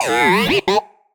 happy2.ogg